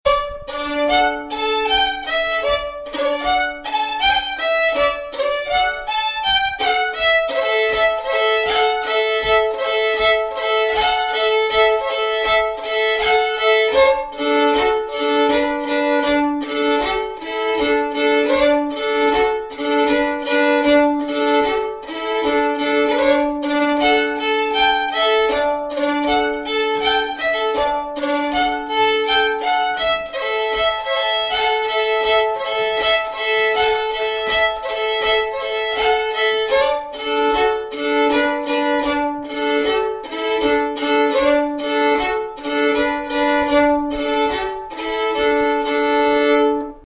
Throughout the duration of UnstFest, we will be recording some of the many events that are scheduled.
Fiddle Workshop - 10.07.09
Keen Fiddlers Being Put Through Their Paces Learning A New Tune
Fiddle Workshop Sample 1 -